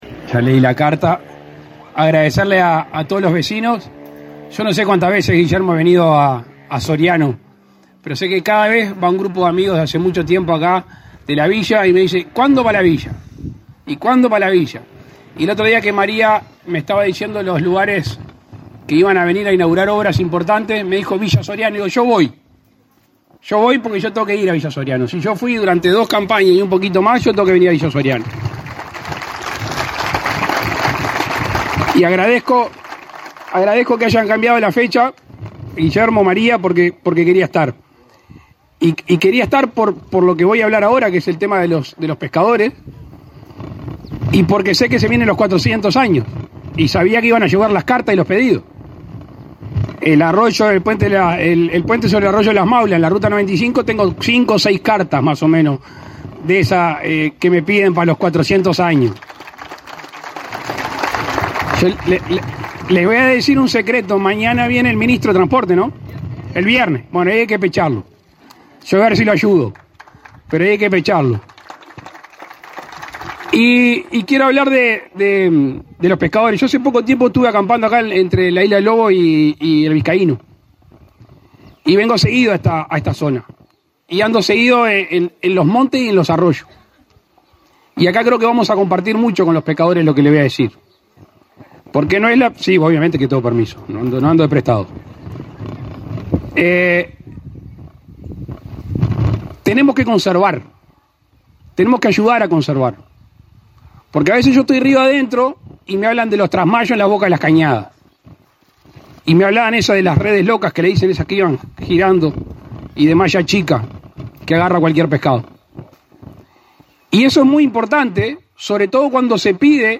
Palabras del presidente de la República, Luis Lacalle Pou
Con la presencia del presidente de la República, Luis Lacalle Pou, se realizó, este 18 de octubre, la inauguración de una planta de acopio de pescado
Lacalle oratoria.mp3